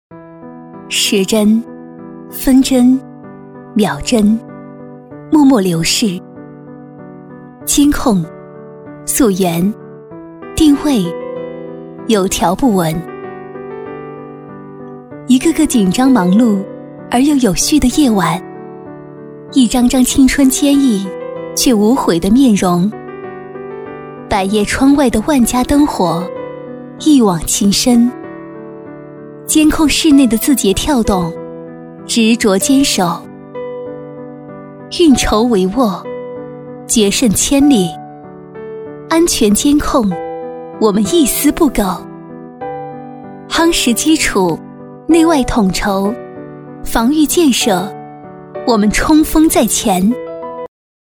普通话配音-配音样音免费在线试听-第4页-深度配音网
女96-旁白-调控中心信息安全处
女96-旁白-调控中心信息安全处.mp3